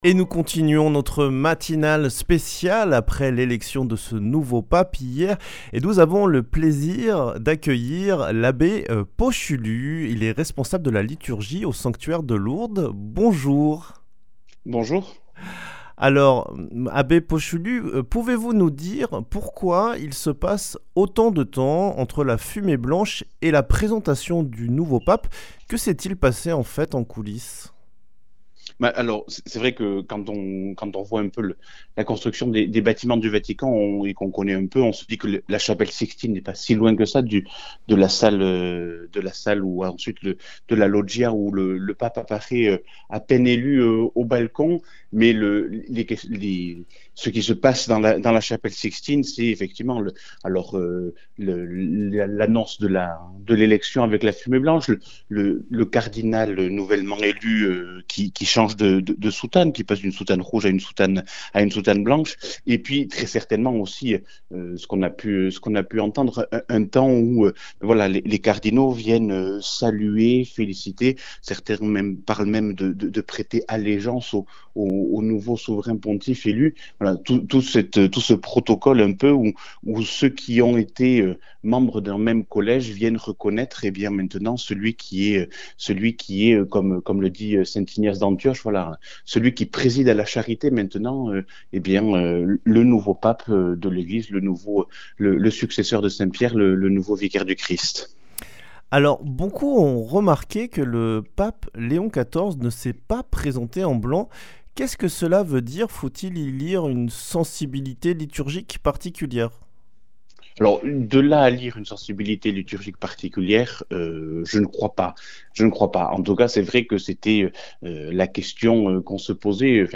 vendredi 9 mai 2025 Le grand entretien Durée 11 min